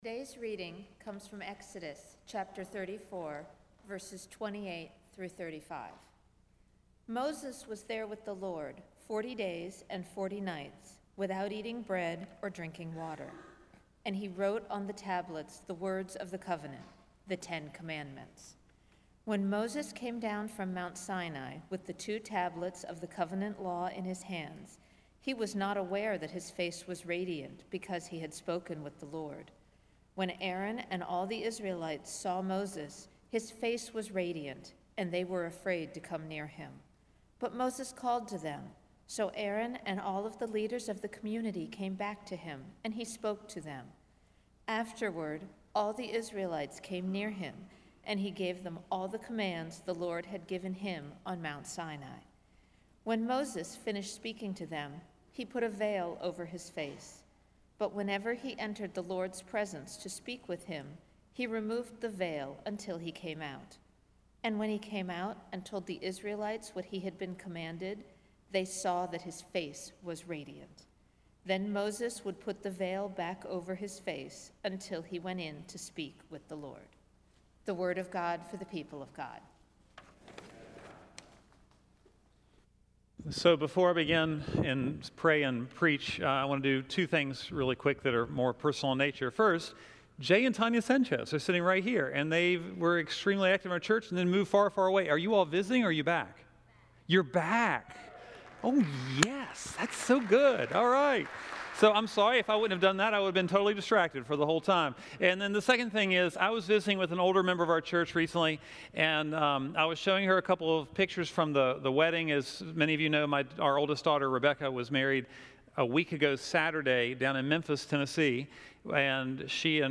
sermon7-6-14.mp3